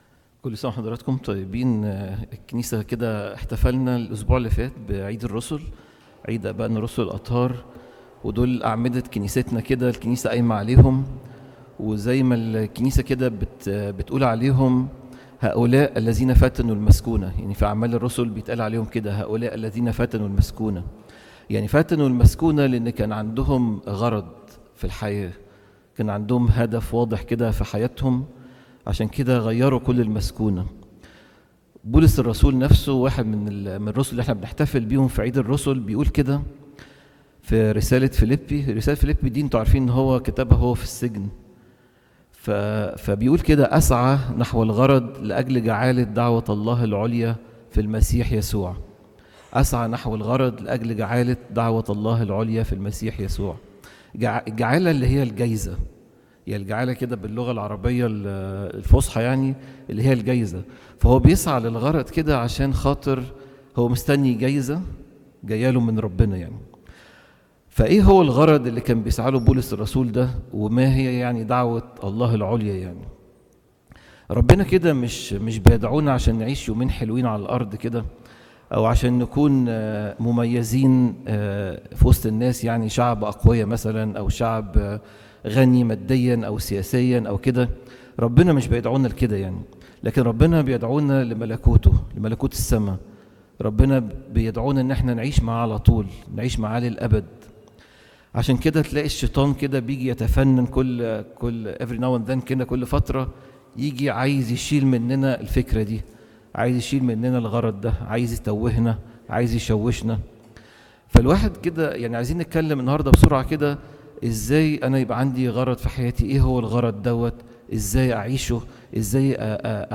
Content Type: Sermon